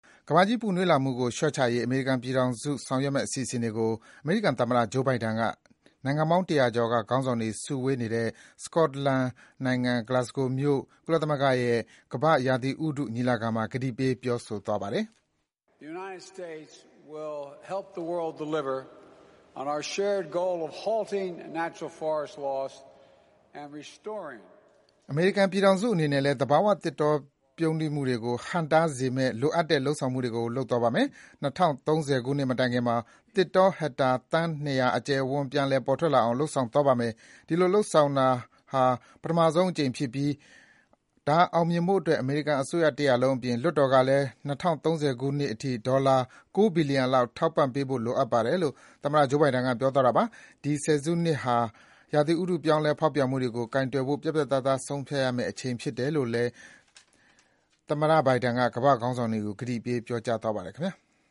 ကမ္ဘာ့ရာသီဥတုညီလာခံမှာ ကန်သမ္မတမိန့်ခွန်းပြော
ရာသီဥတုပြောင်းလဲမှုဆိုင်ရာ COP26 ညီလာခံမှအမေရိကန်သမ္မတ Biden (နိုဝင်ဘာ ၂၊ ၂၀၂၁)